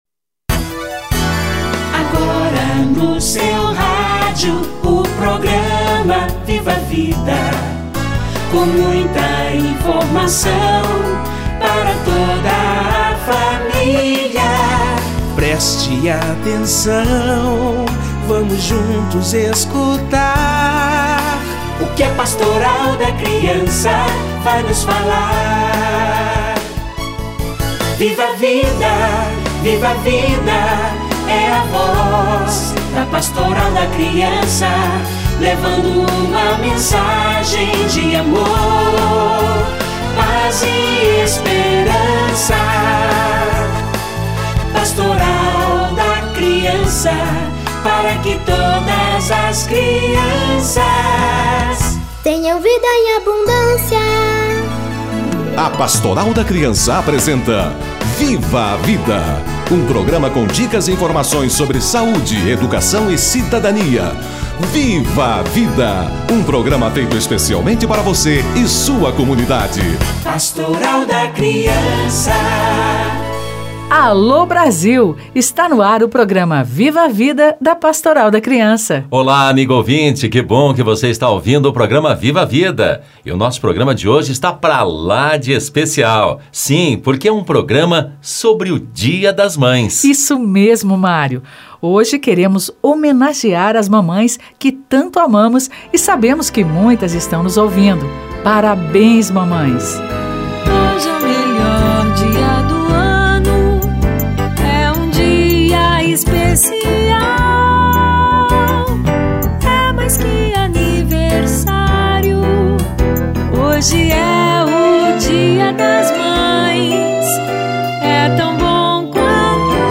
Dia das Mães - Entrevista